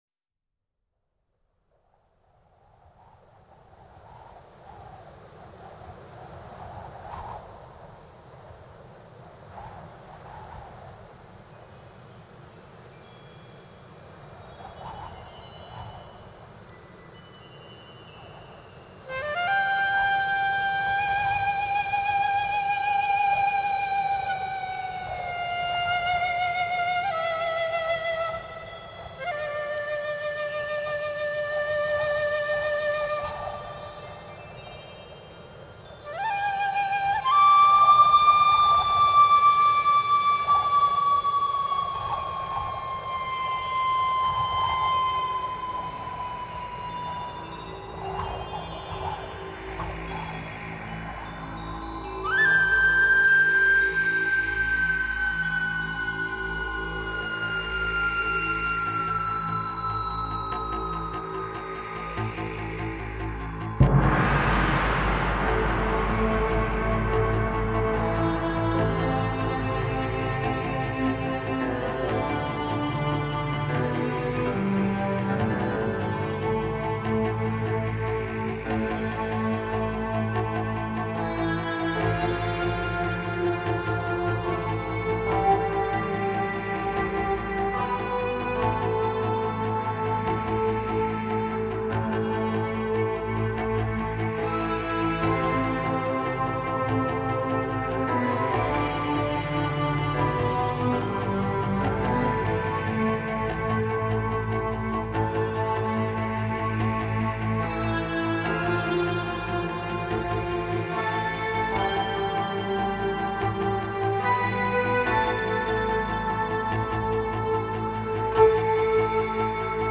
冥想舒缓，耐人寻味
音乐类别：新世纪音乐
使用日本的传统乐器，整张专集相当浓的日本味，融入了大一些大自然的声音，海欧、大海等声音。